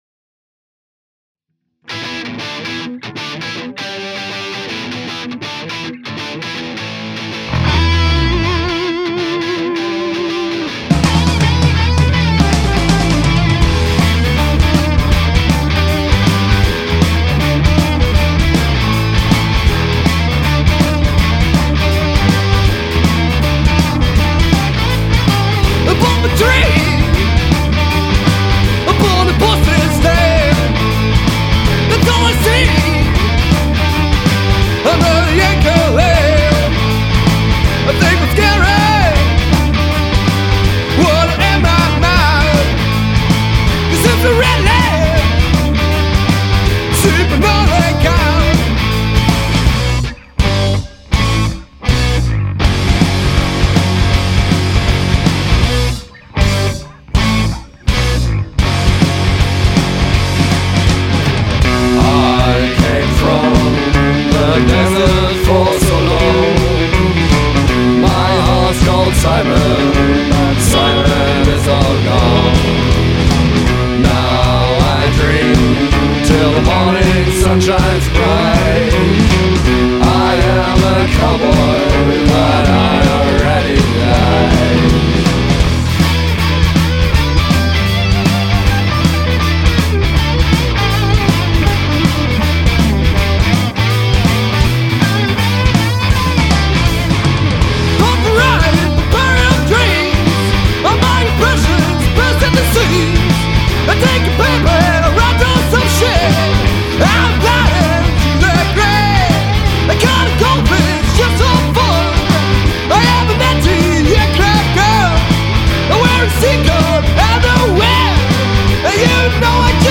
vocals/guitar
leadguitar
drums
bass